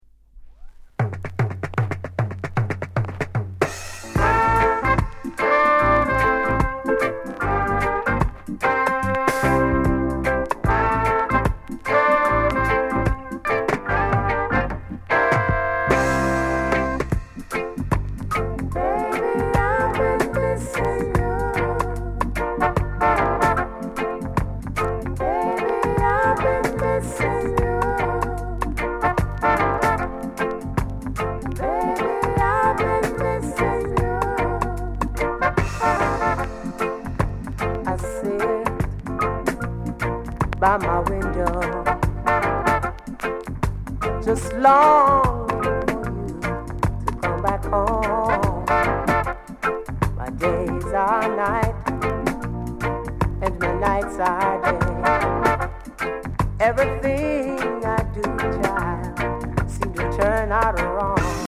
slight warp
LOVERS ROCK